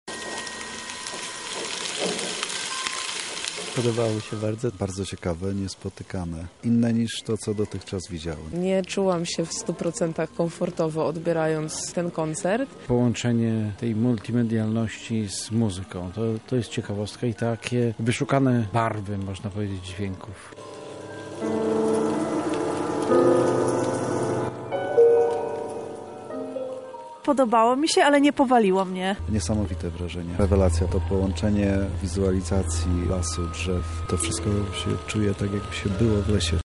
Swoimi wrażeniami podzieli się z nami sami widzowie.